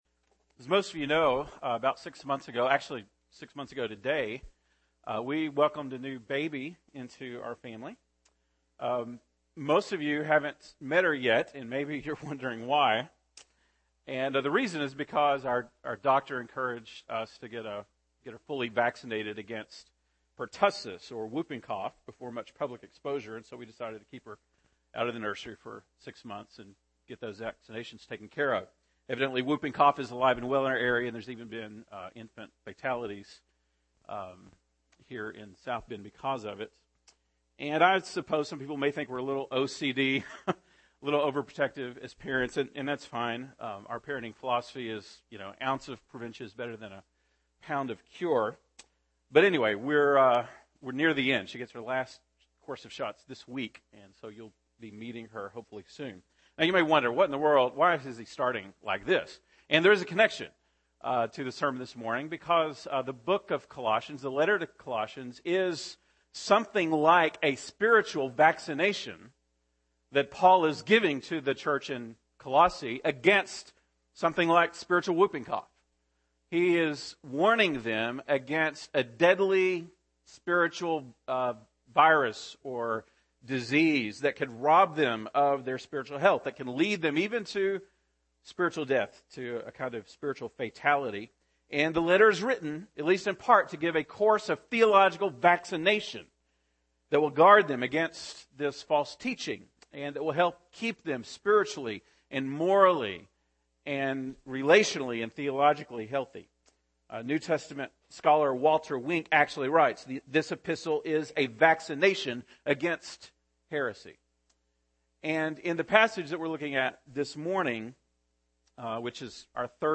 February 10, 2013 (Sunday Morning)